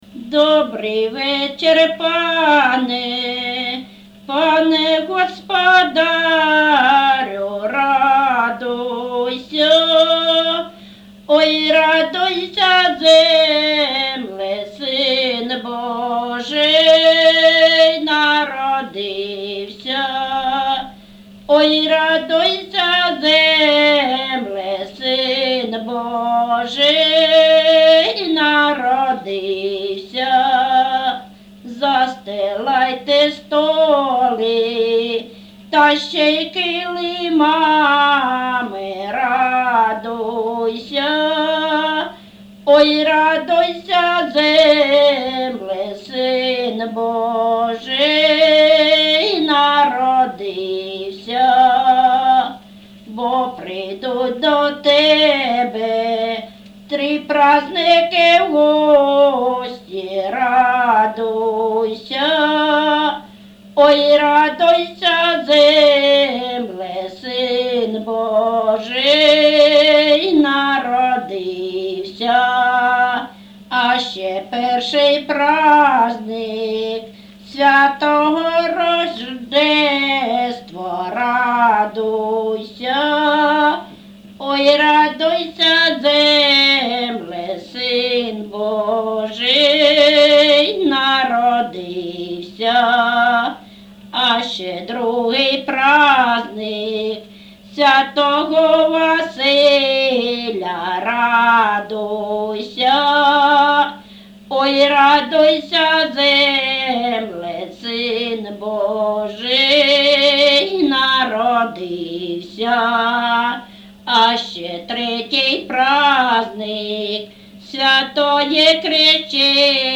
ЖанрКолядки
Місце записум. Старобільськ, Старобільський район, Луганська обл., Україна, Слобожанщина